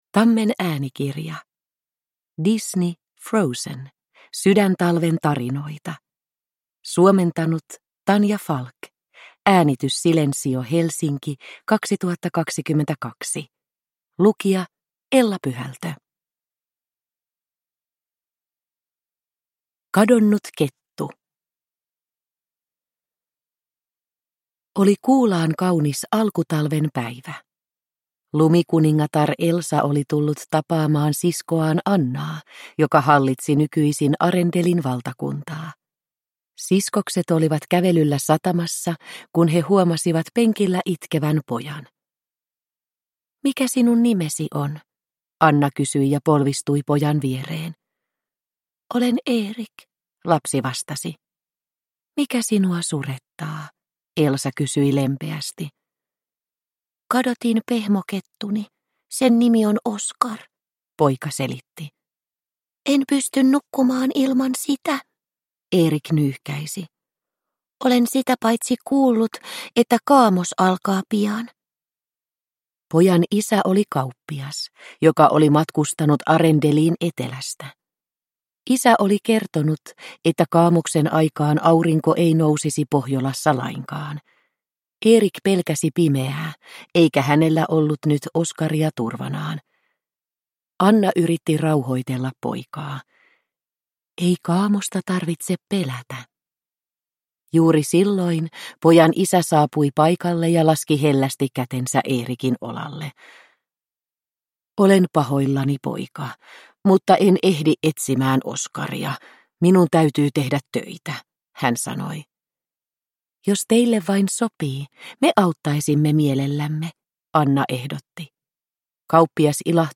Disney. Frozen. Sydäntalven tarinoita – Ljudbok – Laddas ner